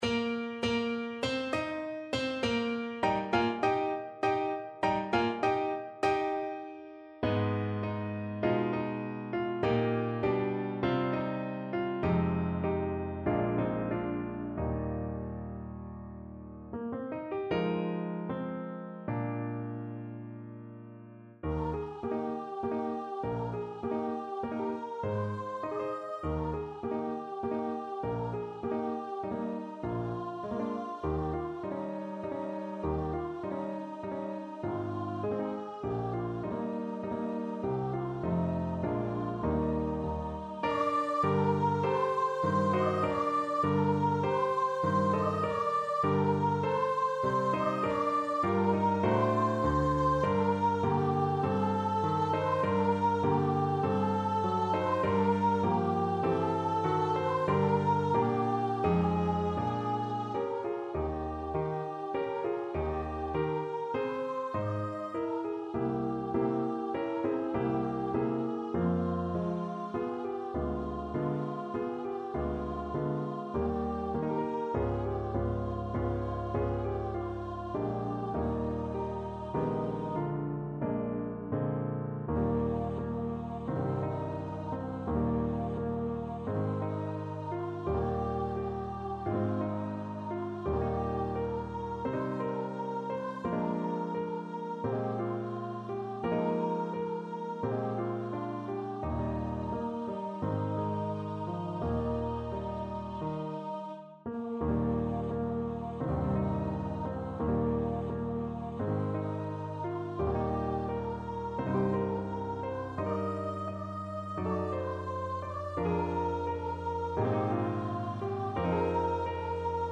4/4 (View more 4/4 Music)
Moderato
Classical (View more Classical Voice Music)